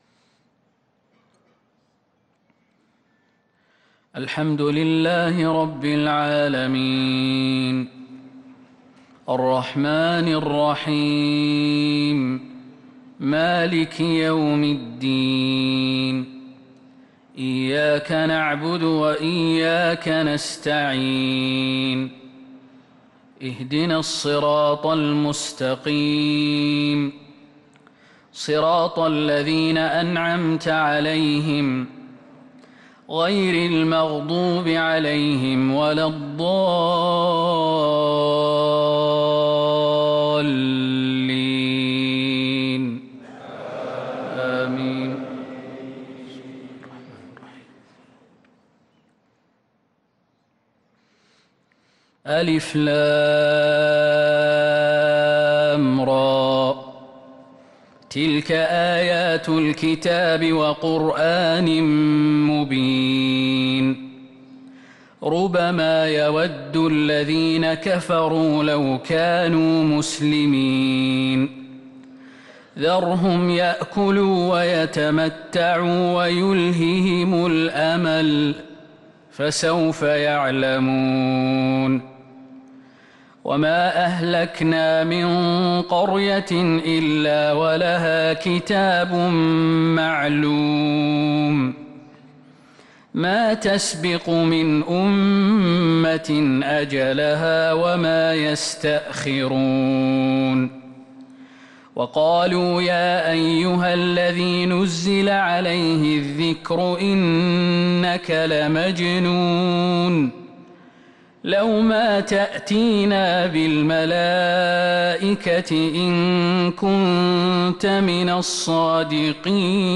صلاة الفجر للقارئ خالد المهنا 23 محرم 1445 هـ